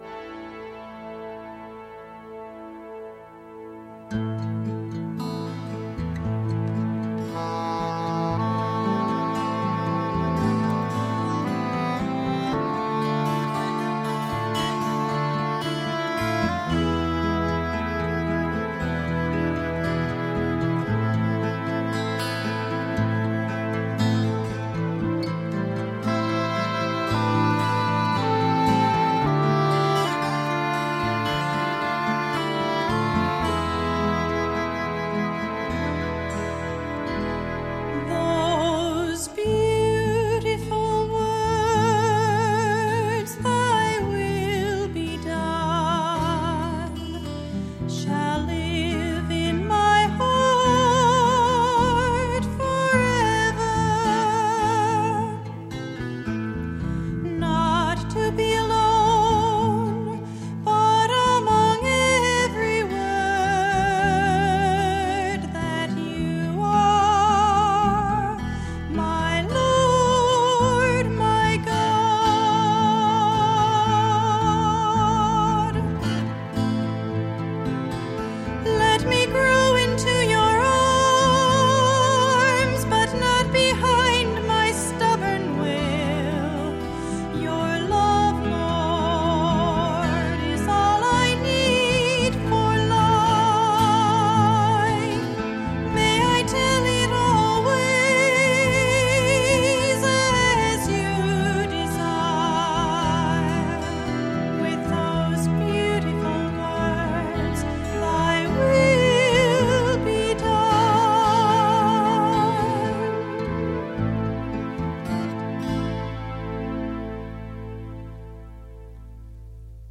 Piano
Electric & acoustic guitars
Bass guitar
Percussion
Trumpet
English horn, saxophone and clarinet
Background vocals